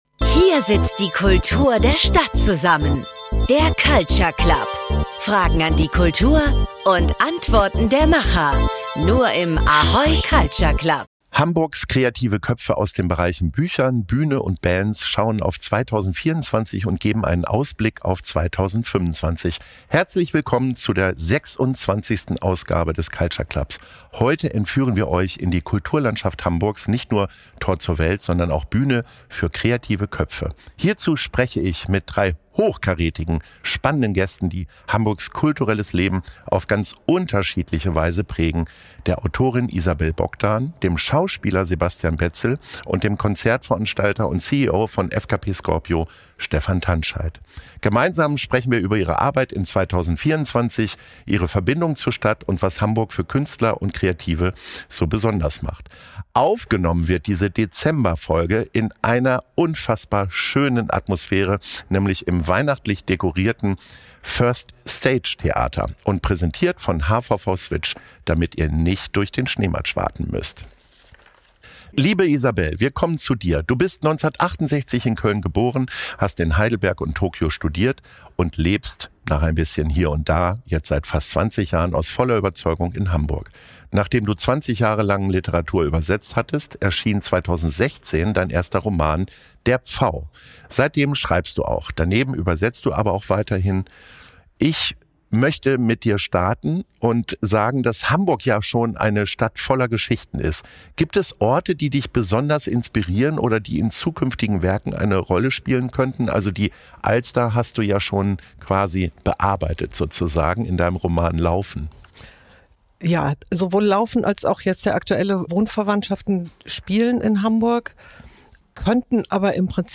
Aufgenommen auf der festlich geschmückten Bühne des First Stage Theaters, bringt diese Dezember-Ausgabe nicht nur weihnachtlichen Glanz, sondern auch spannende Einblicke und inspirierende Anekdoten direkt in eure Ohren.